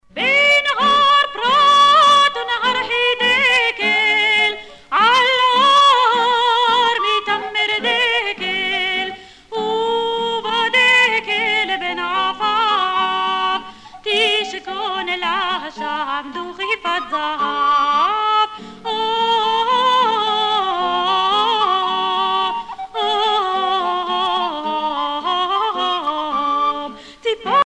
Chants et danses des pionniers
Pièce musicale éditée